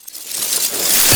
casting_charge_matter_grow_04.wav